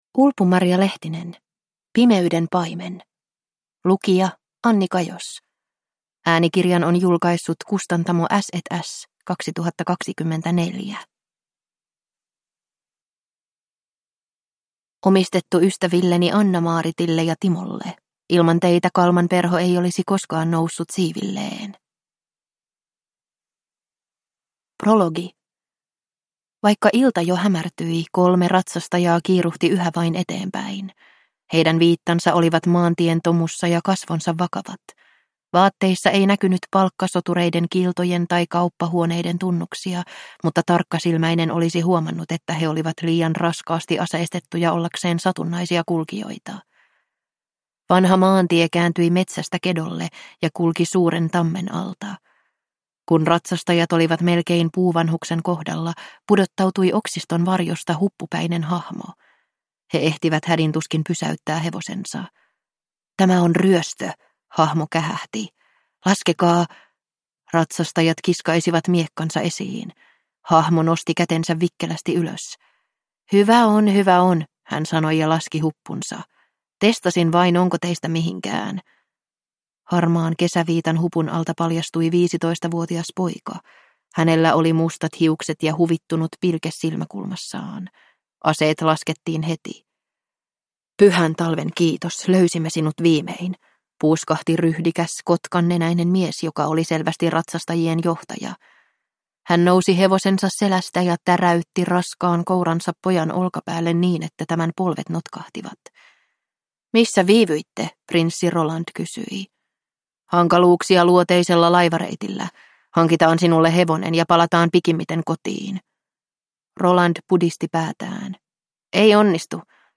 Pimeyden paimen – Ljudbok